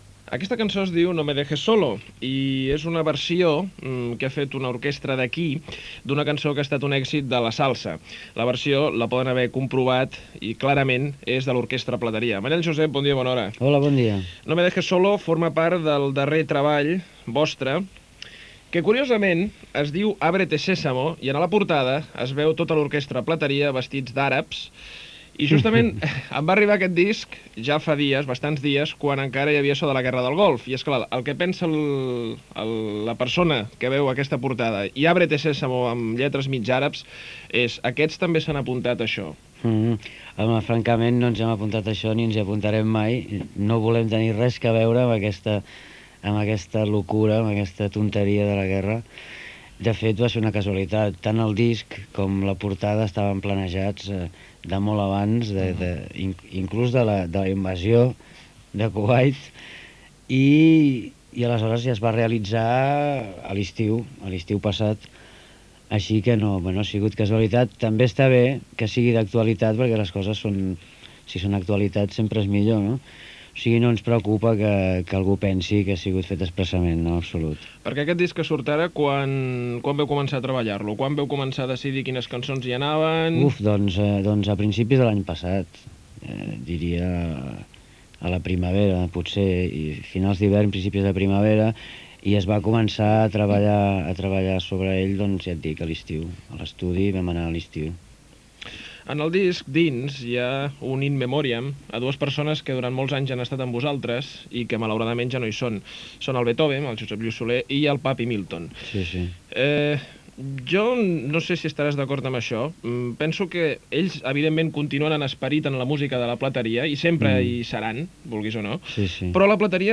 Entrevista
Gènere radiofònic Info-entreteniment